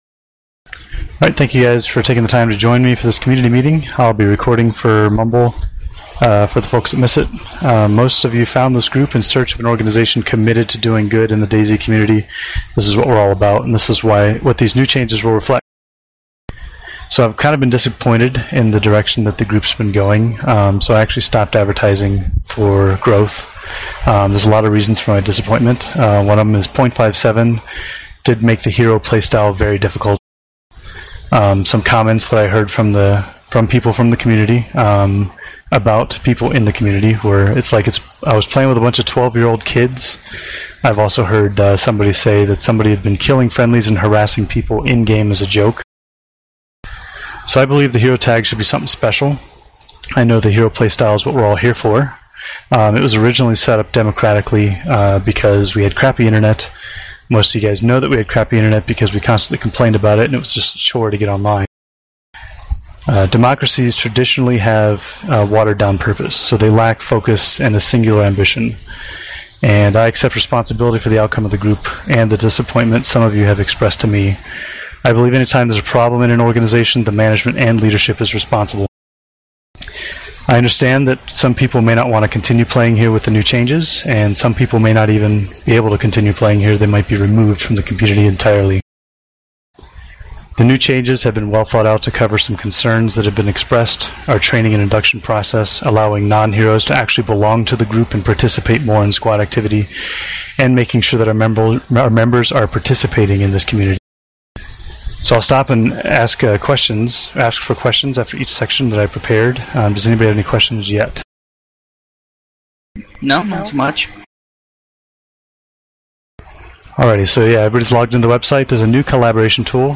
This is the recording from our Community Meeting discussing the new changes to the Hostile Environment Relief Organization. Please forgive my Mumble settings, I keep cutting out at the end of my sentences.